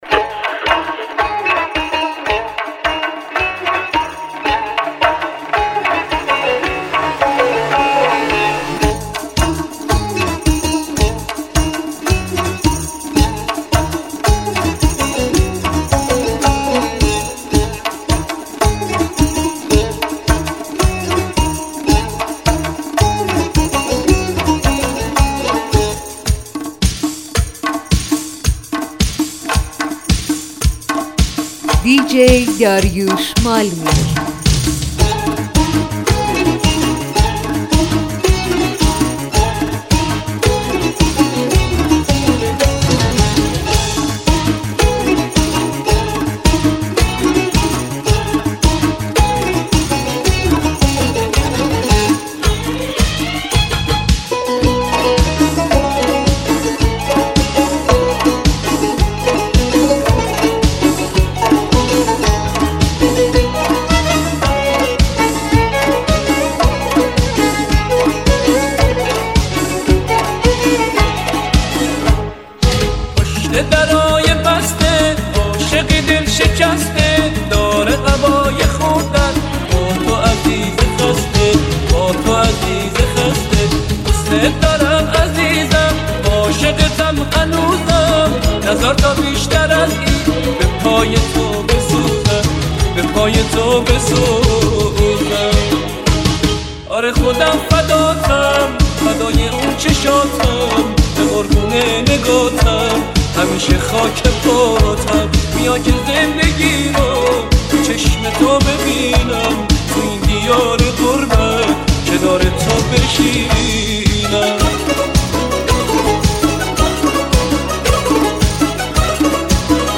شاد و غمگین
خوانندهٔ موسیقی پاپ و سنتی